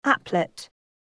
ap·plet 미 [ǽplət] 듣기반복듣기 영 [ǽplət] 듣기반복듣기 바로저장 단어장 선택 후 저장 문법/뜻관련어/학습예문 검색결과명사[컴퓨터] 애플릿: 특히 자바 프로그램에 이용되는 작은 응용 프로그램으로 특화된 효과를 낼 때 사용됨.